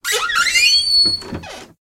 door.ogg.mp3